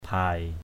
/bʱaɪ/ (d.) rái cá = loutre. mbeng ikan yau bhai (tng.)